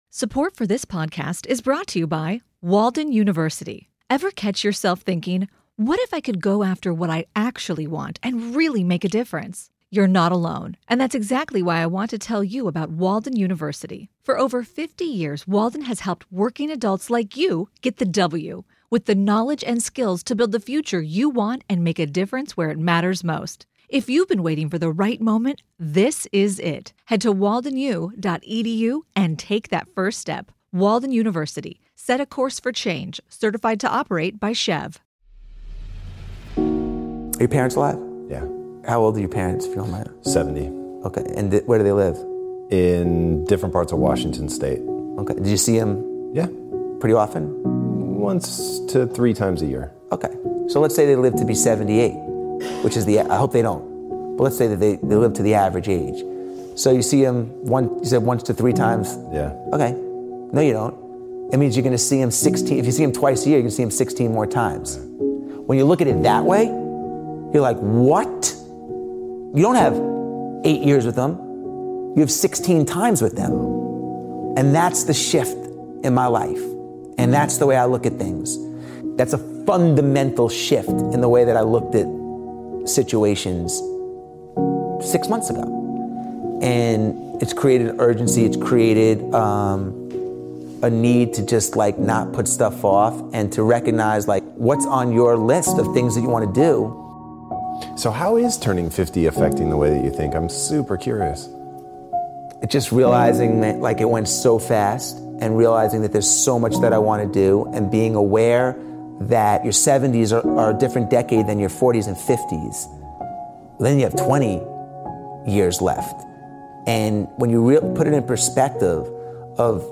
Speaker: Jesse Itzler